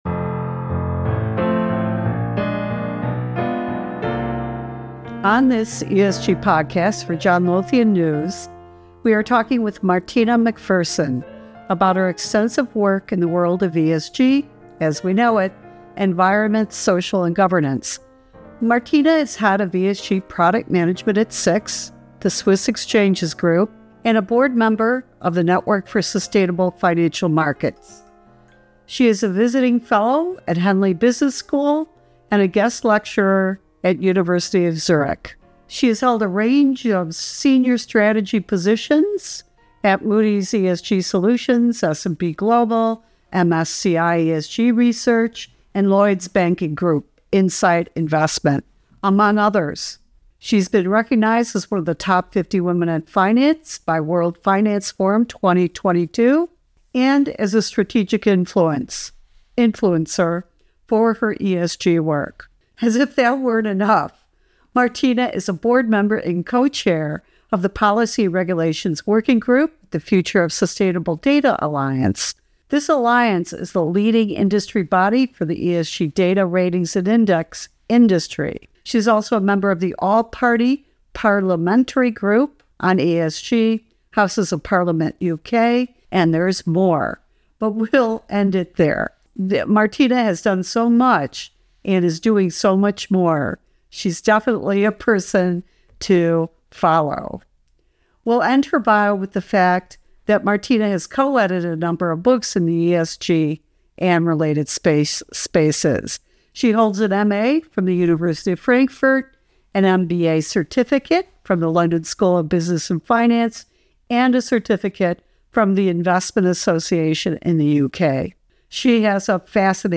In a wide-ranging conversation